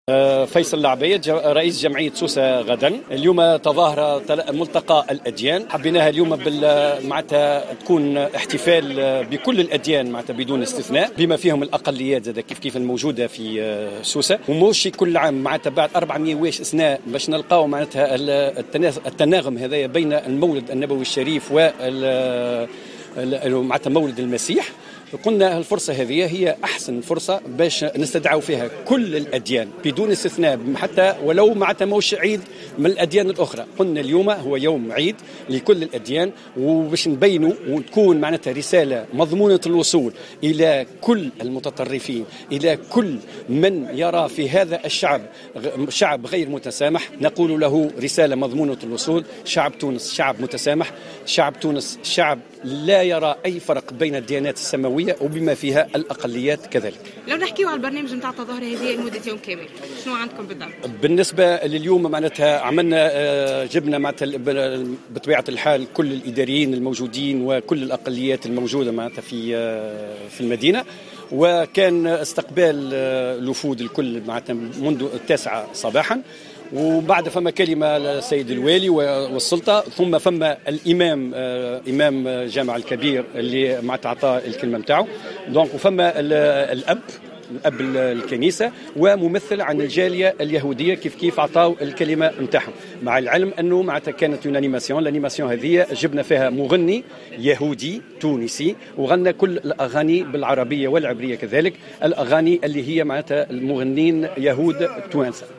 شهد المتحف الأثري بسوسة اليوم 24 ديسمبر 2014 بمناسبة الاحتفال بالمولد النبوي الشريف و بالتزامن مع ميلاد المسيح تظاهرة بعنوان ملتقى الأديان الذي جمع كل الأقليات الموجودة في سوسة.